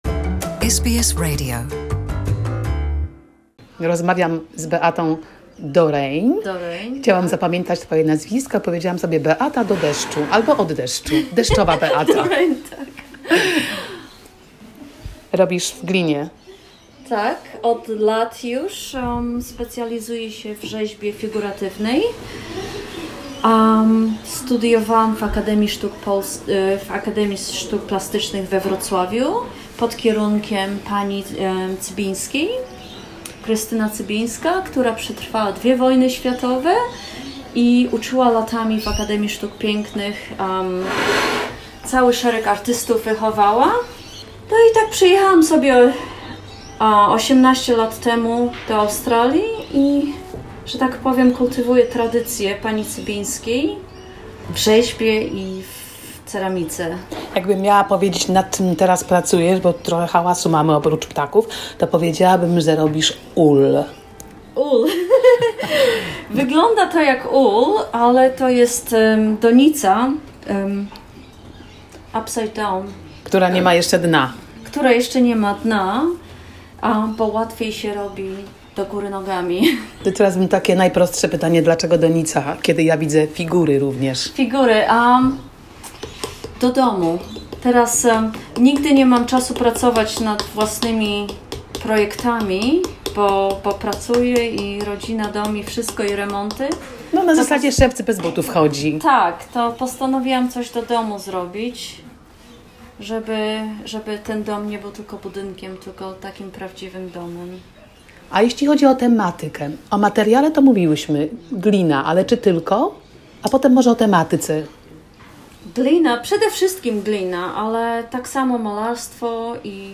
A conversation with young artist